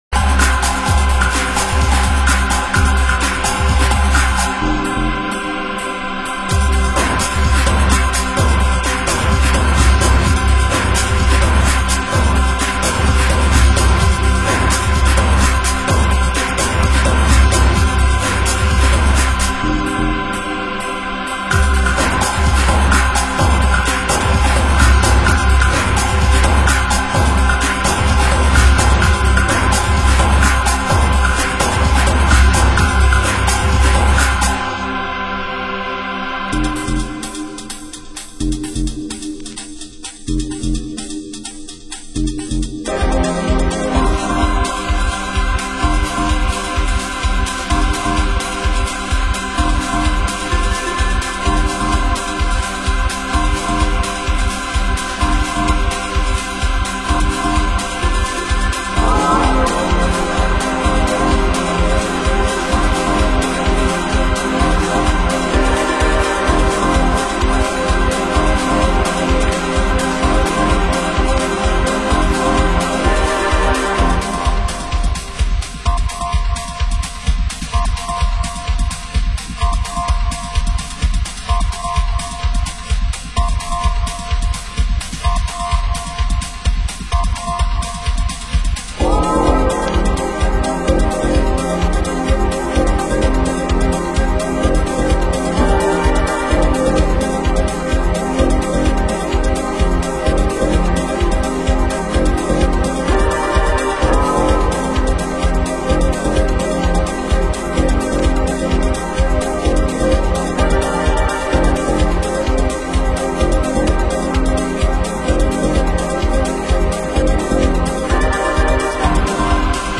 Genre: Euro Techno